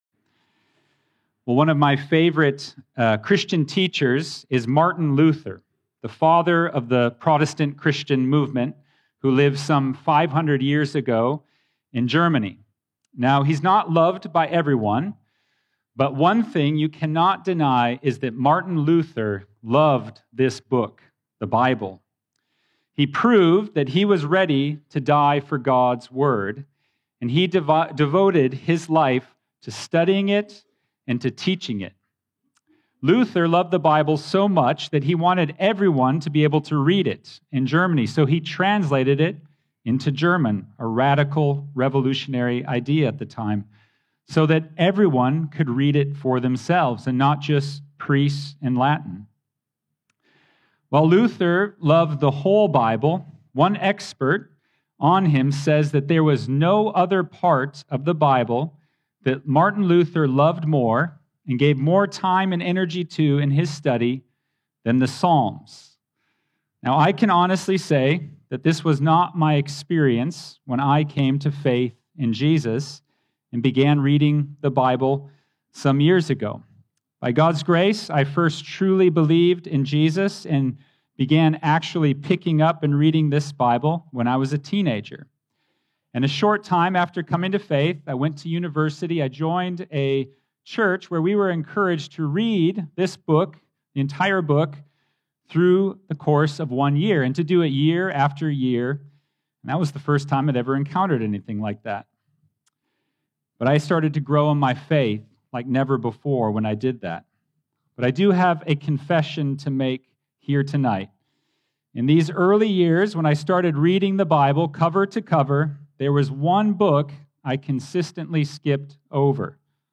Grace Church Abu Dhabi Sermons